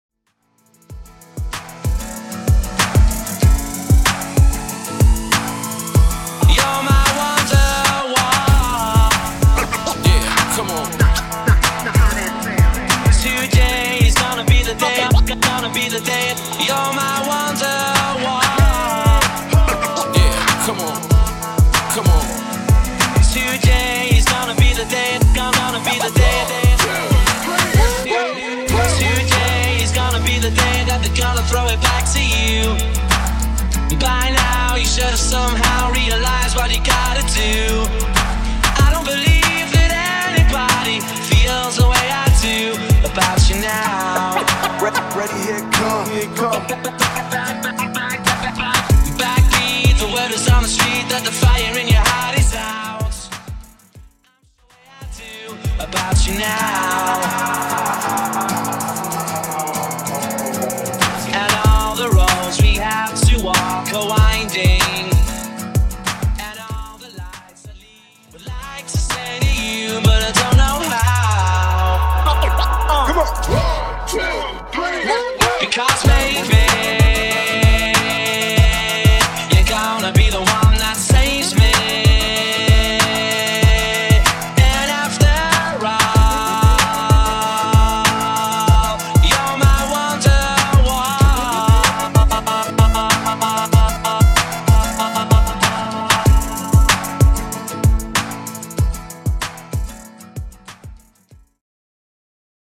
70's